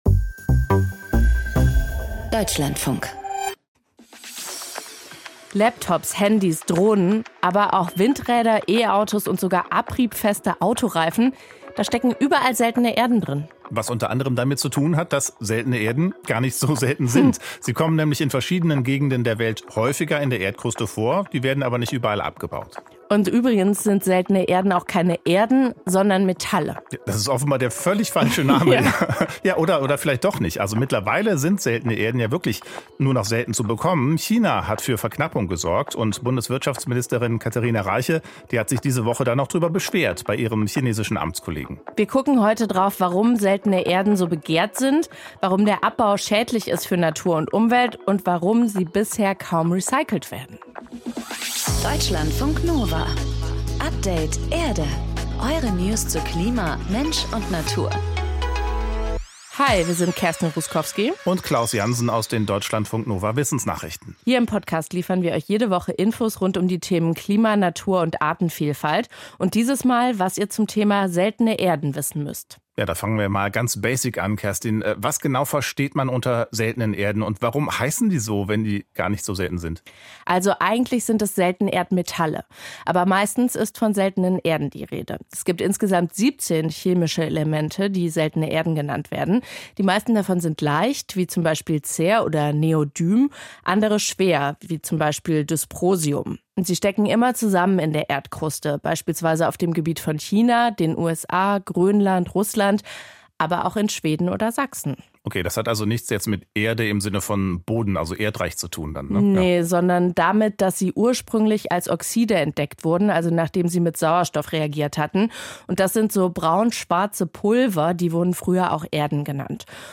Natursound: Vietnamesische Frösche auf Reisfeld